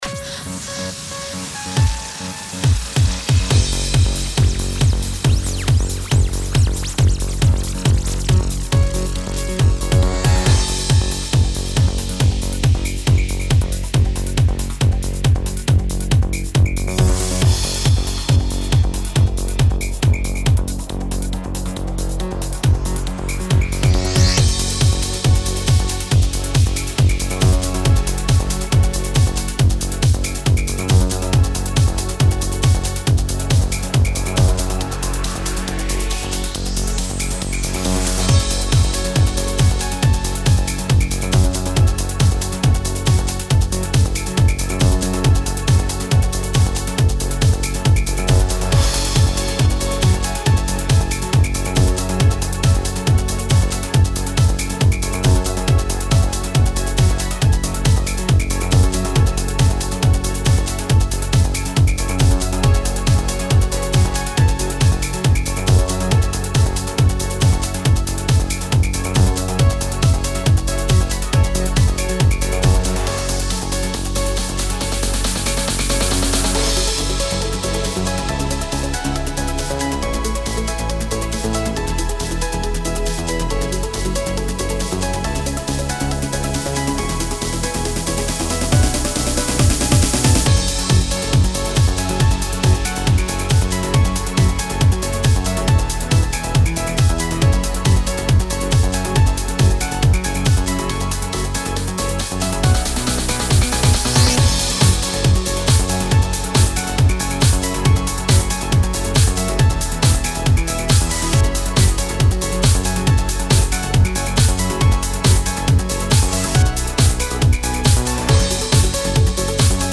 Genre: Progressive.